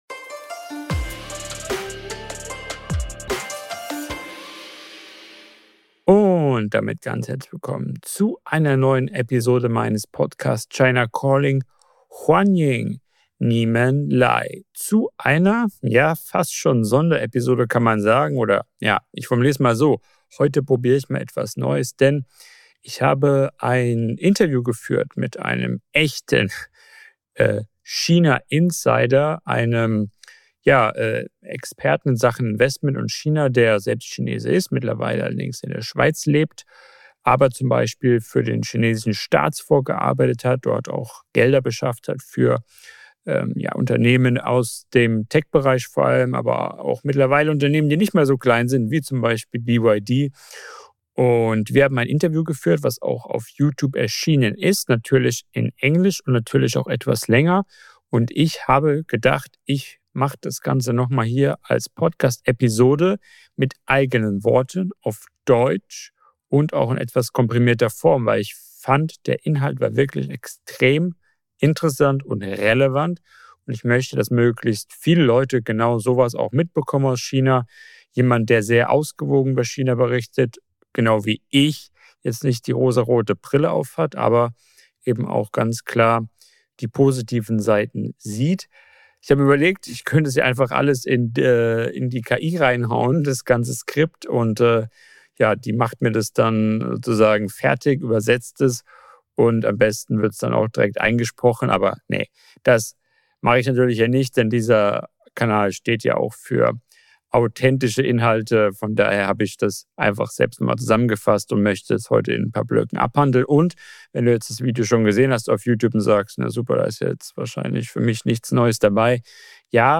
Für den Podcast habe ich die wichtigsten Punkte und einige Ergänzungen auf Deutsch mit eigenen Worten zusammengefasst.